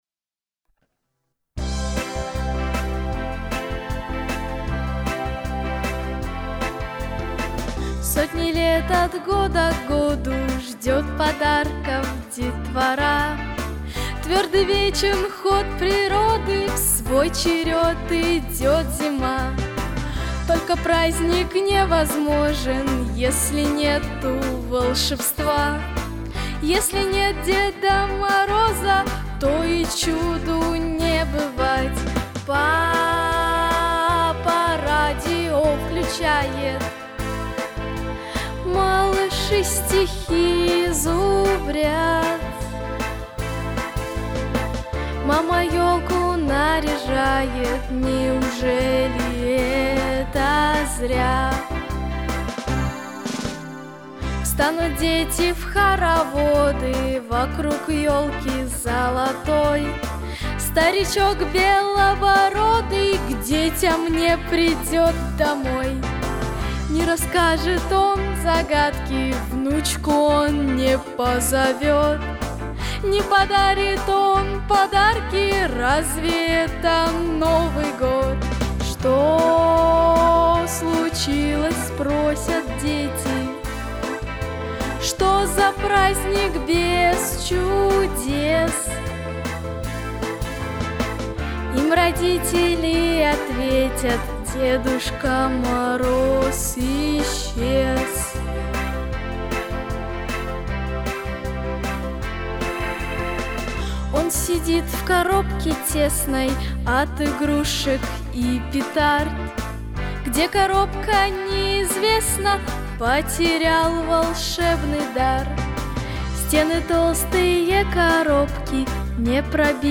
Записано в студии Easy Rider в декабре 2019 года